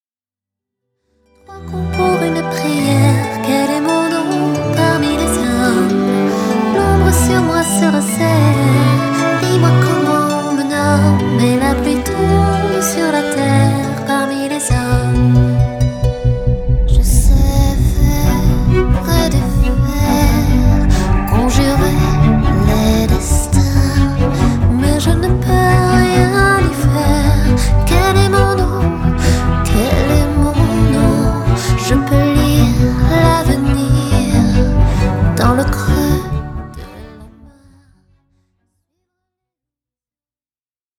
harpiste chanteuse